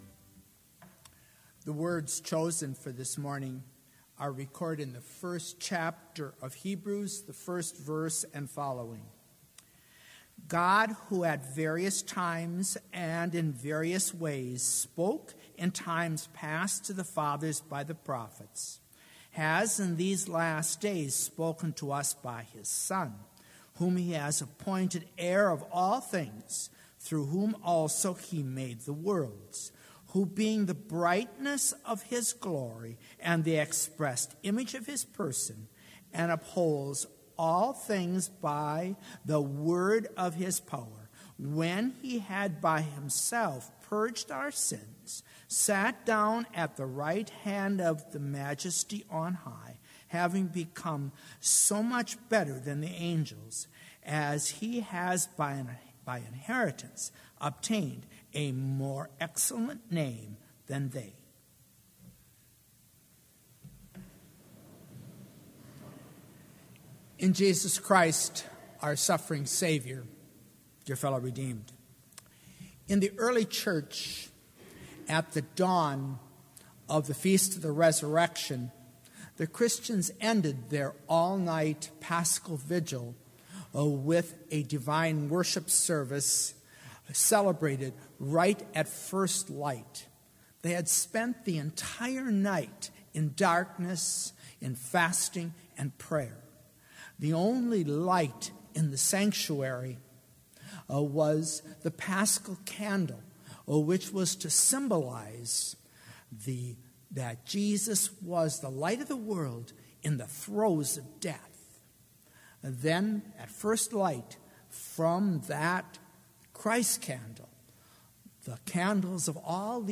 Complete service audio for Chapel - April 1, 2019